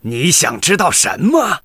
文件 文件历史 文件用途 全域文件用途 Rt_tk_03.ogg （Ogg Vorbis声音文件，长度1.6秒，116 kbps，文件大小：23 KB） 源地址:游戏语音 文件历史 点击某个日期/时间查看对应时刻的文件。 日期/时间 缩略图 大小 用户 备注 当前 2018年5月20日 (日) 14:53 1.6秒 （23 KB） 地下城与勇士  （ 留言 | 贡献 ） 分类:诺顿·马西莫格 分类:地下城与勇士 源地址:游戏语音 您不可以覆盖此文件。